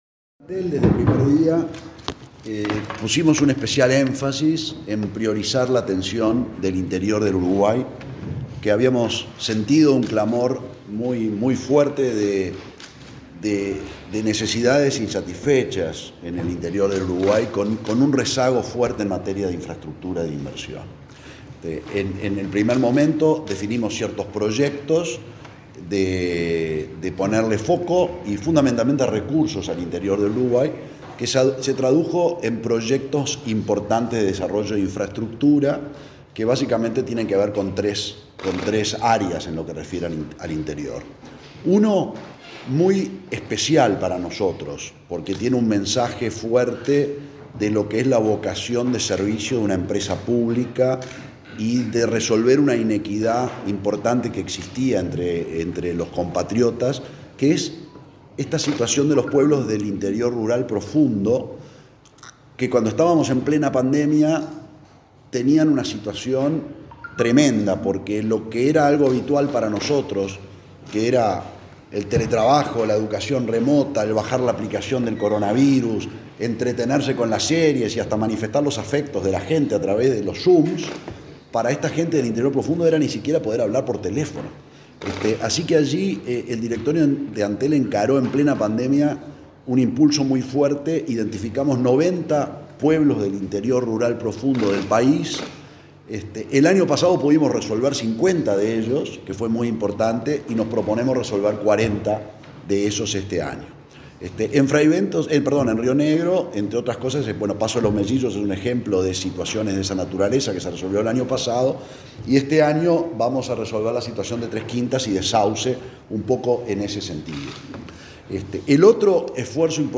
Conferencia del presidente de Antel, Gabriel Gurméndez
Este martes 24, el presidente de Antel, Gabriel Gurméndez, brindó una conferencia de prensa en la ciudad de Fray Bentos, para informar sobre las obras